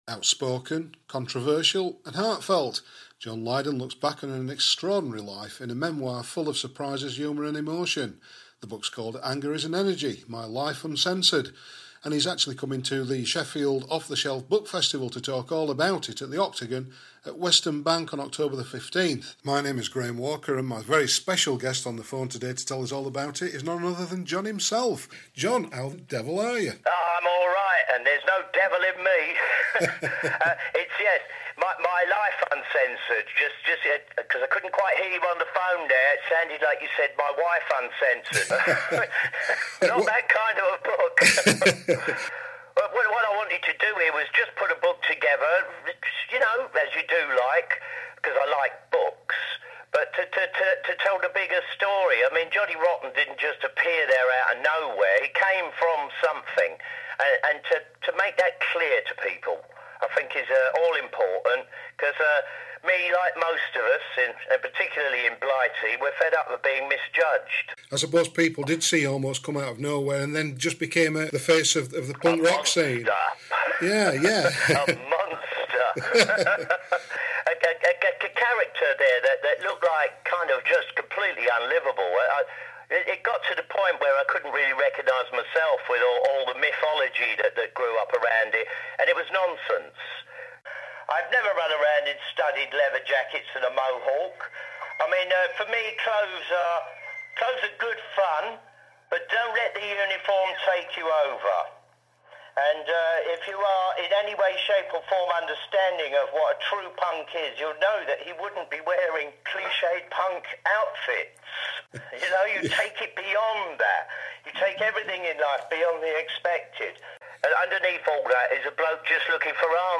Interview: John Lydon 'Johnny Rotten'
Punk legend John Lydon, best known to a generation as Johnny Rotten of the Sex Pistols, talks to me from his LA home about his new autobiography, Anger Is An Energy - My Life Uncensored, ahead of his guest talk at Sheffield's Off The Shelf book festival on October 15.